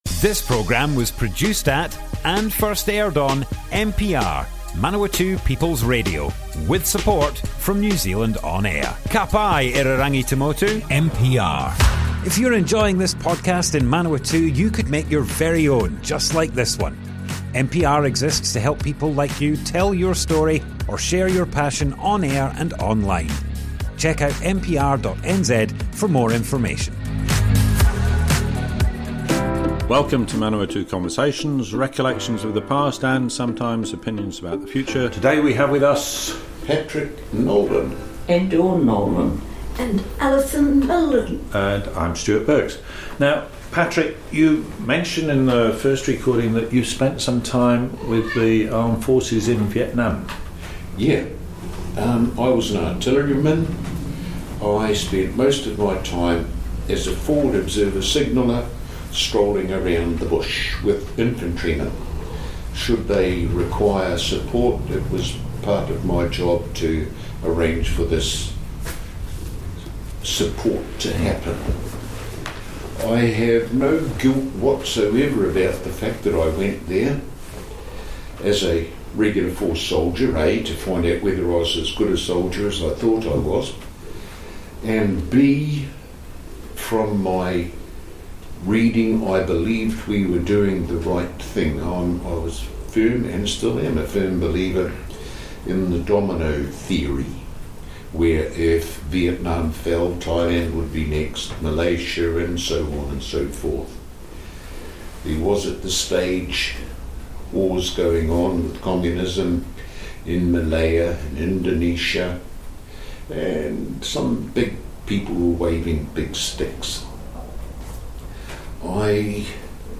Manawatu Conversations More Info → Description Broadcast on Manawatu People's Radio, 12th July 2022, Part 2 of 5.
oral history